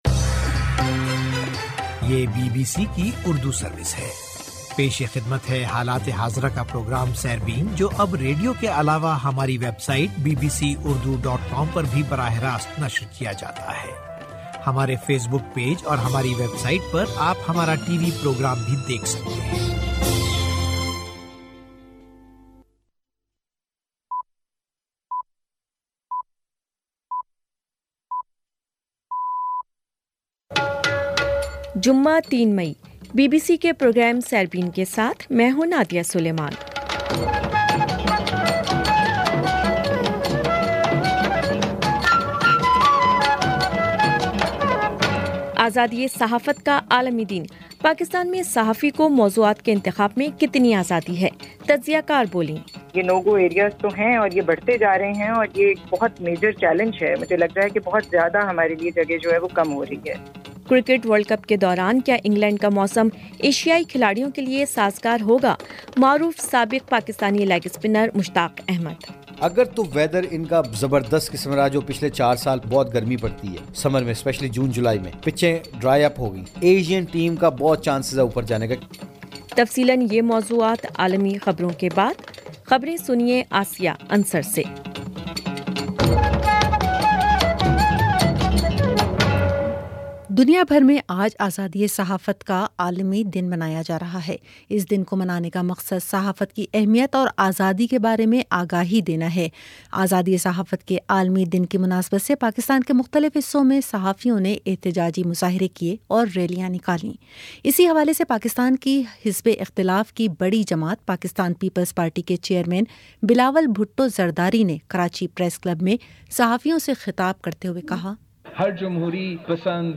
جمعہ 03 مئ کا سیربین ریڈیو پروگرام